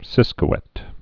(sĭskə-wĕt)